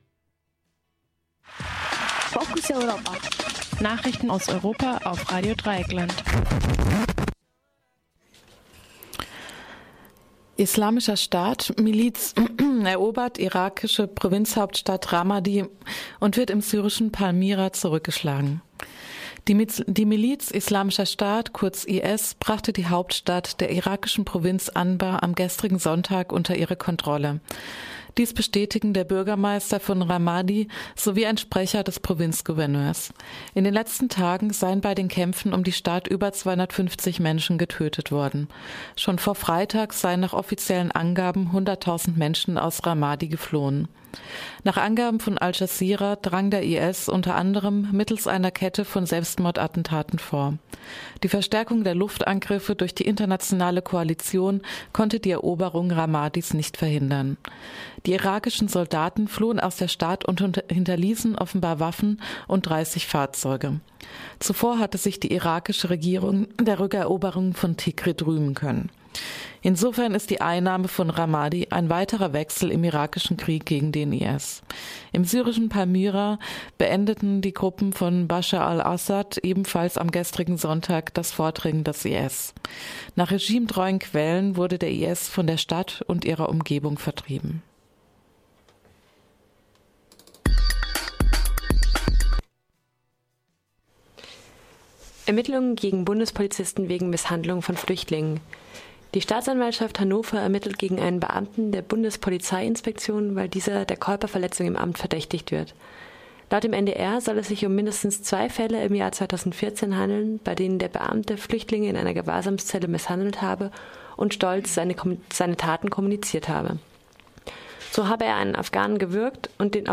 Focus Europa Nachrichten vom 18.5.2015